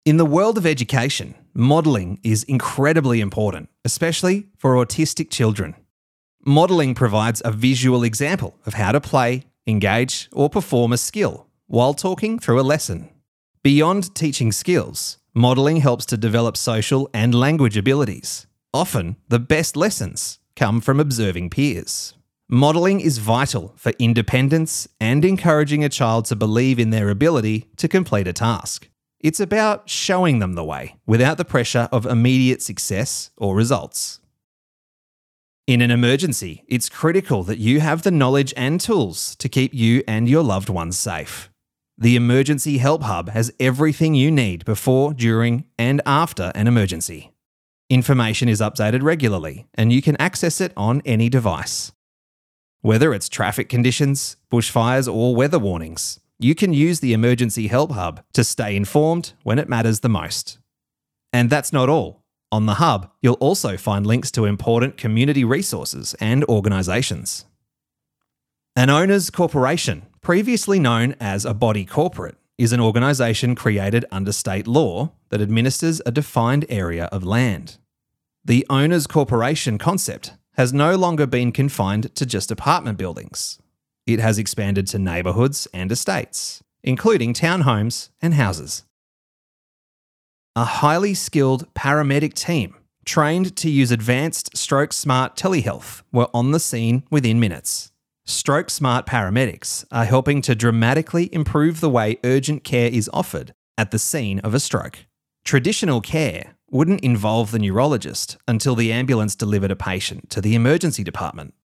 Male
Relatable, conversational male Australian voice.
Natural, friendly, warm, approachable, affable, every-man style Australian voice.
Narration
Narration, E-Learning, Doco